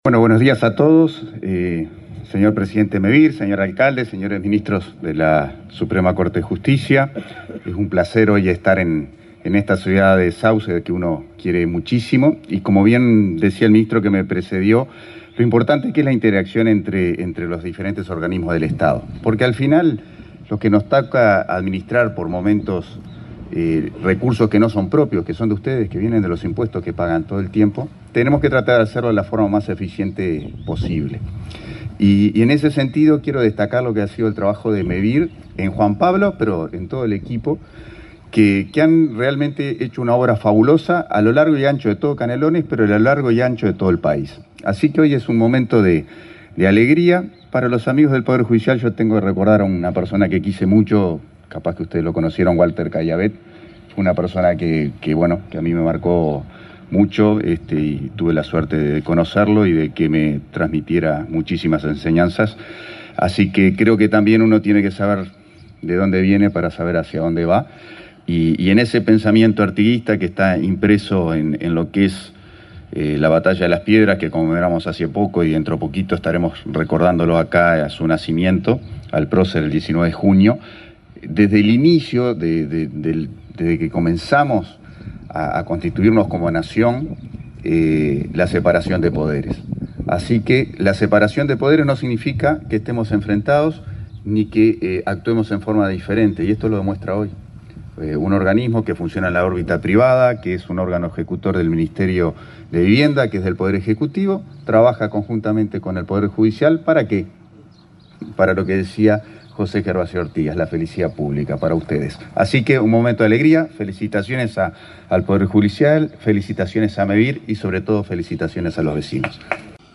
Palabras de autoridades en acto en Sauce
Este martes 21, el subsecretario de Vivienda, Tabaré Hackenbruch, y el presidente de Mevir, Juan Pablo Delgado, participaron en la inauguración de un